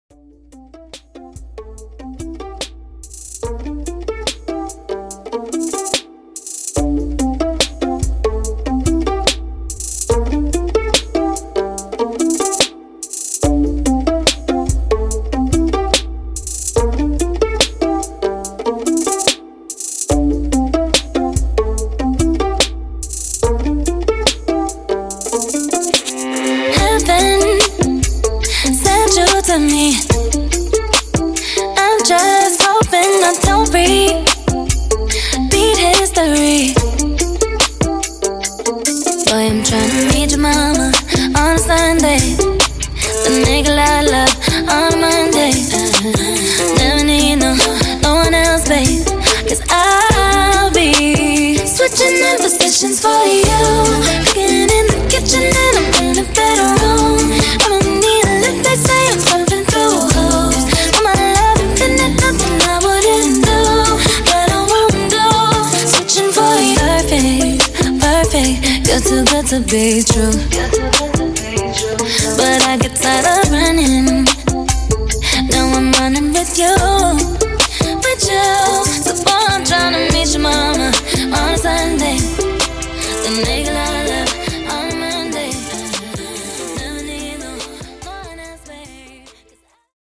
R&B ReDrum)Date Added